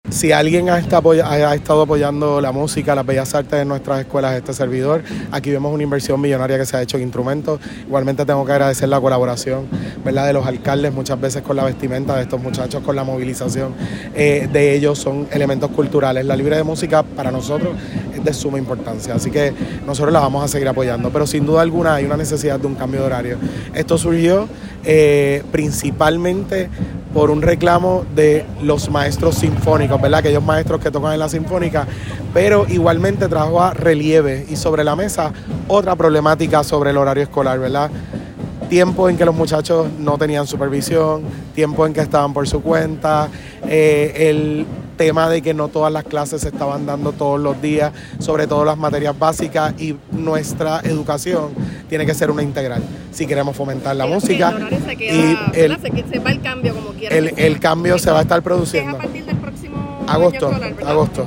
Las expresiones del secretario se dieron durante la congregación de bandas escolares de toda la isla, las cuales fueron desfilando por toda la avenida Carlos Chardón, en ruta hacia el DE en plena semana educativa.